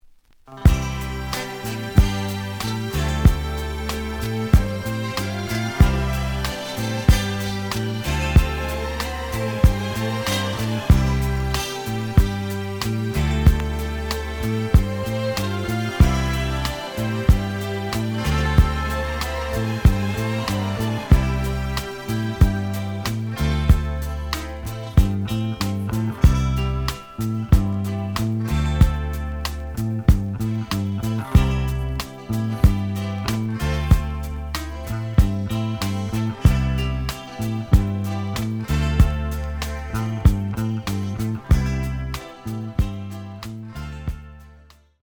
The audio sample is recorded from the actual item.
●Genre: Soul, 70's Soul
Slight edge warp. But doesn't affect playing. Plays good.)